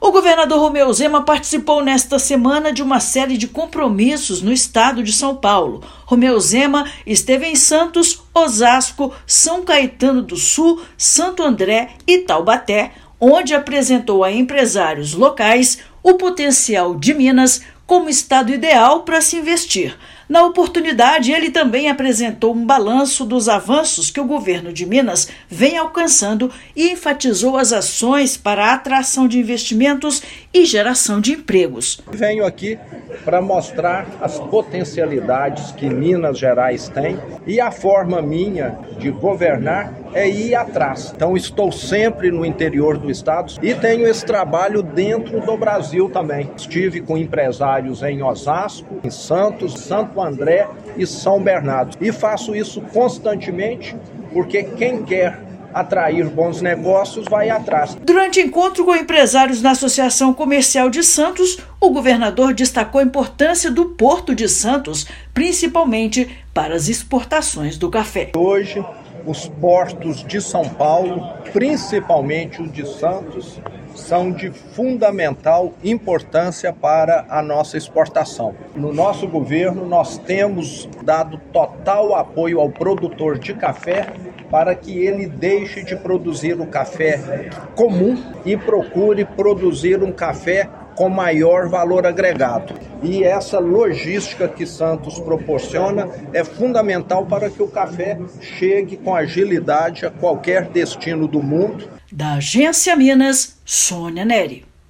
Potencial do estado para atração de novos negócios foi apresentado em associações comerciais; sinergia entre MG e SP também foi enfatizada para garantir a qualidade e a agilidade nas exportações. Ouça matéria de rádio.